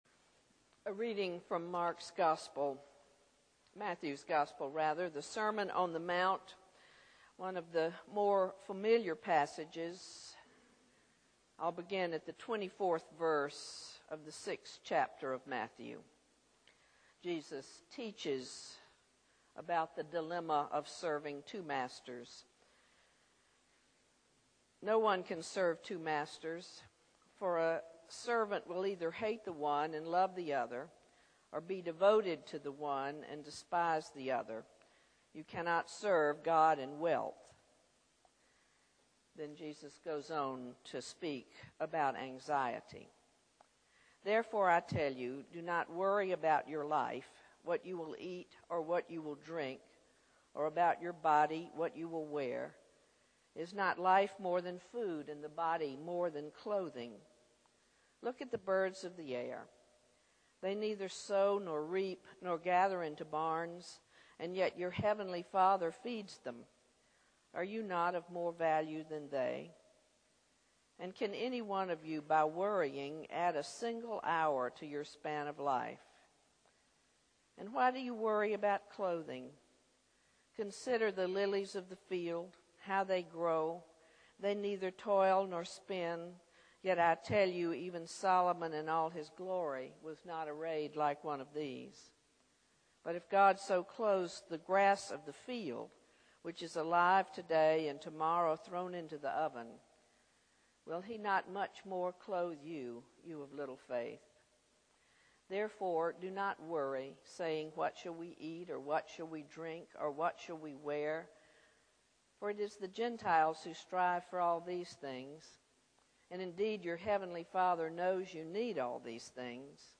THE SERMON "Worry Workout"